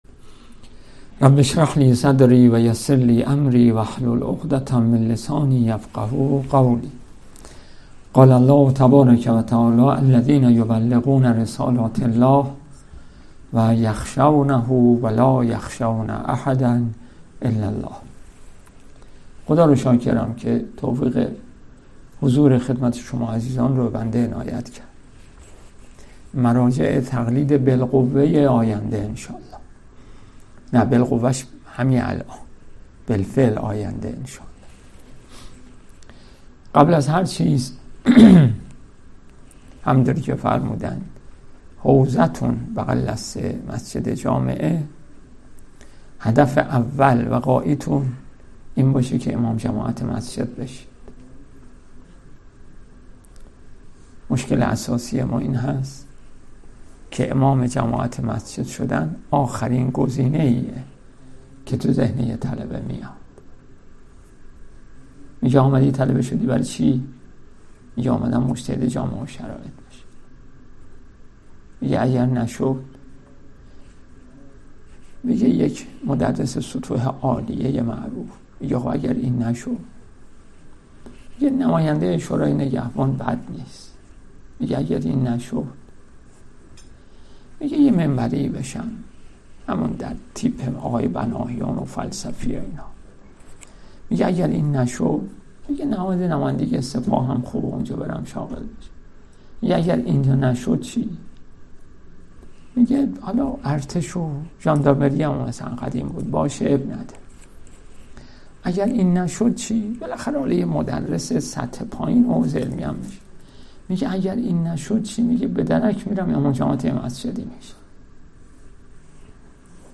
صوت سخنرانی‌ها | وظایف روحانیون در عصر حاضر و تحلیل اتفاقات روز